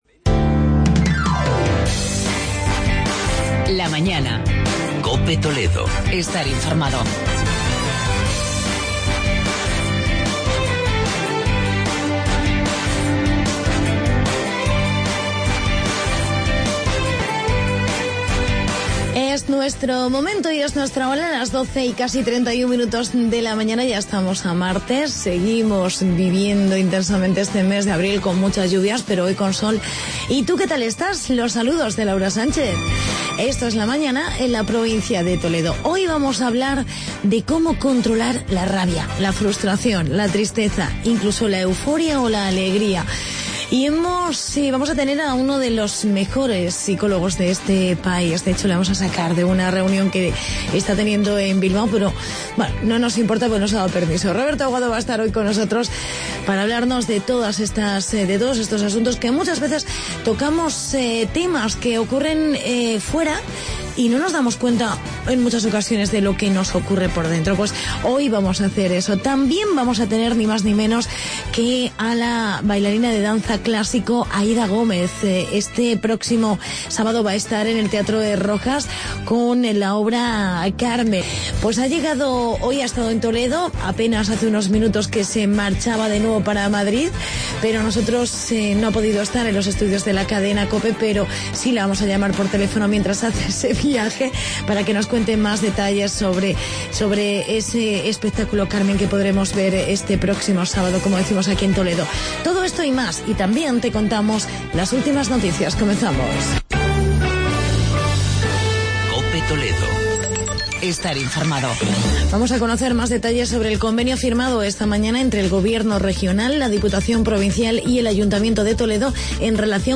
Entrevista
Hablamos con la bailarina y coreógrafa Aída Gómez que dirige "Carmen", espectáculo de danza clásica.